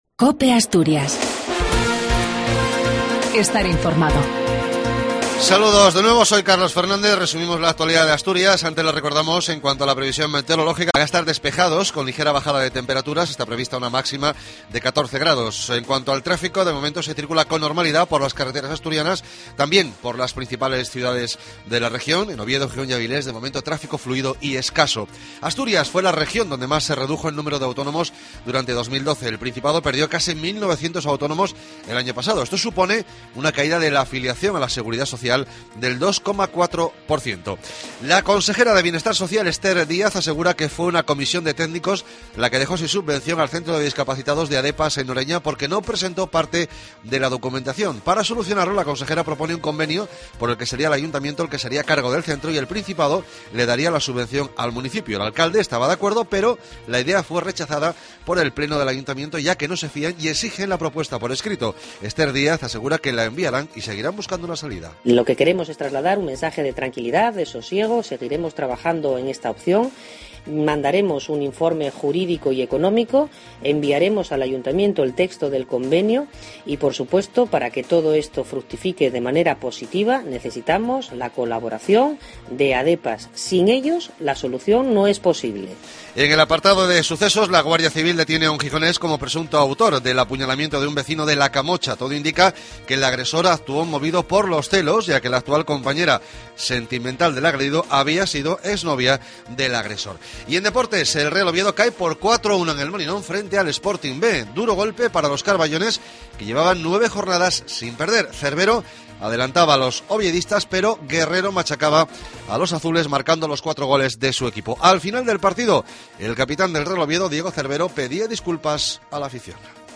AUDIO: LAS NOTICIAS DE ASTURIAS A PRIMERA HORA DE LA MAÑANA.